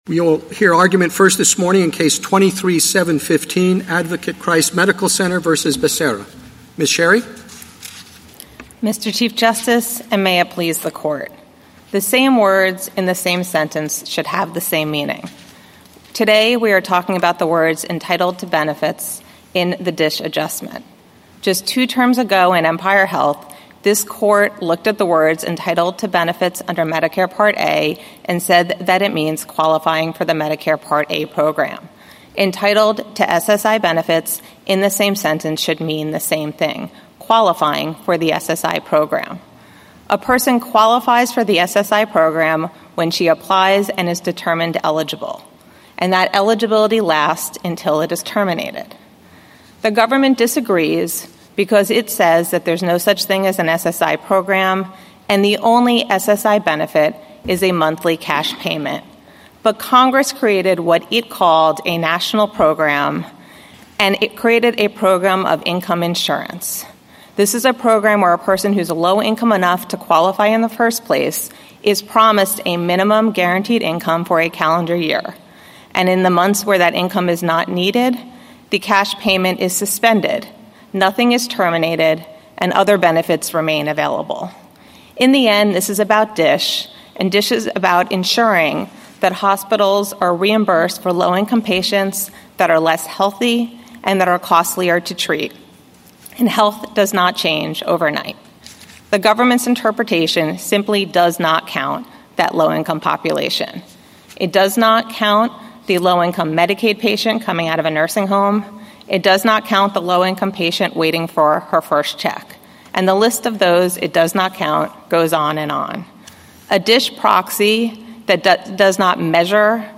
Oral Argument - Audio